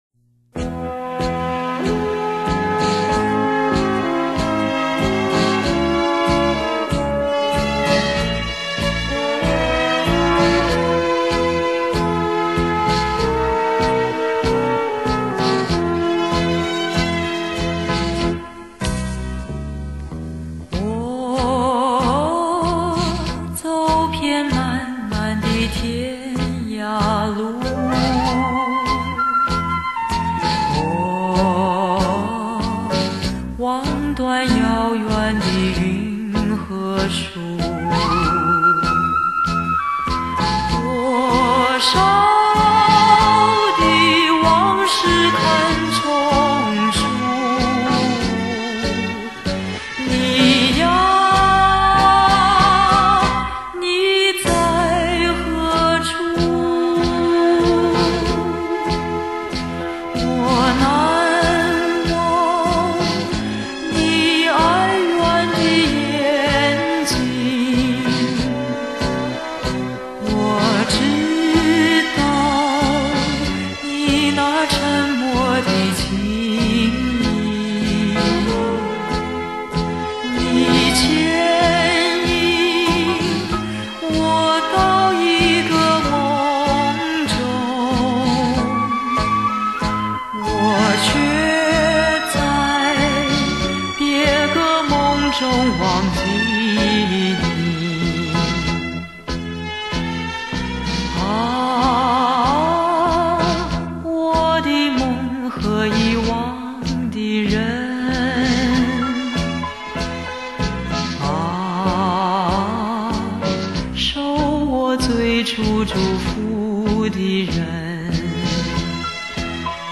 时光流逝，她的歌声明净醇厚不变；爱情老了，她的微笑沉静温暖不老……